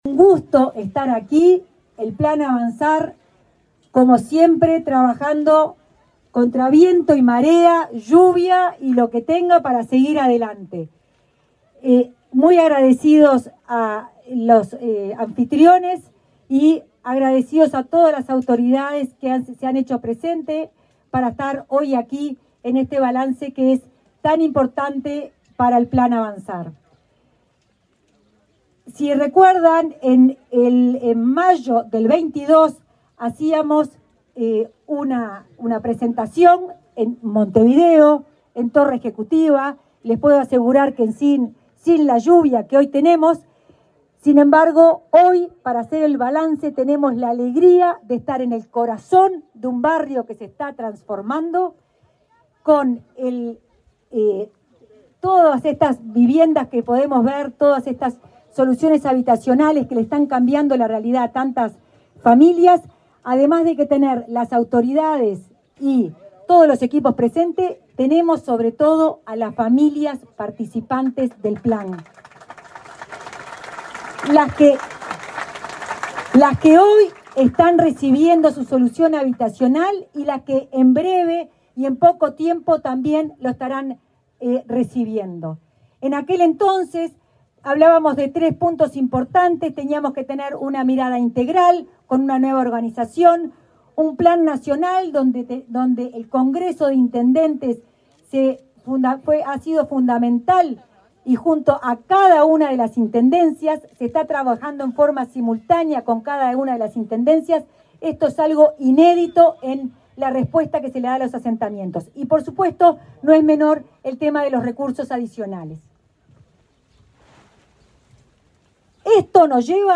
Palabras de autoridades del Ministerio de Vivienda en Paysandú
La directora de Integración Social y Urbana del Ministerio de Vivienda y Ordenamiento Territorial, Florencia Arbeleche, y el titular de la cartera,